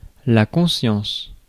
Ääntäminen
IPA: /kɔ̃.sjɑ̃s/